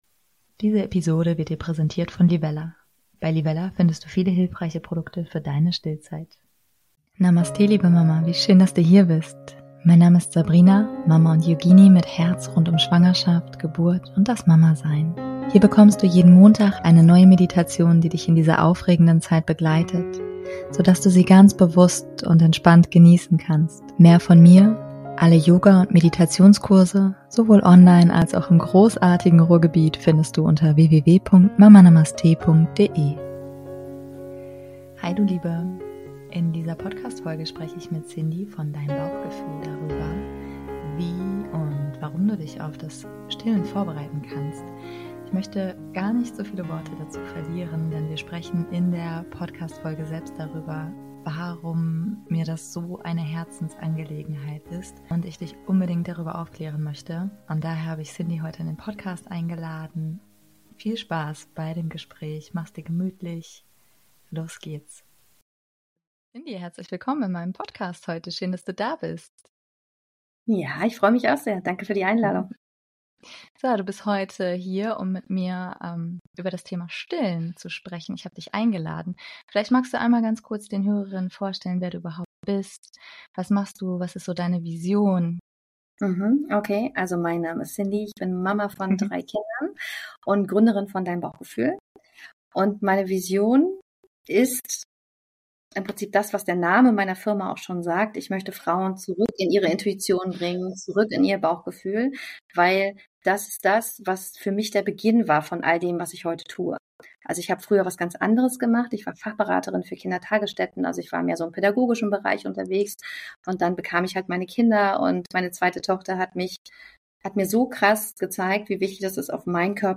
#179 - Wieso du dich unbedingt aufs Stillen vorbereiten solltest - Interview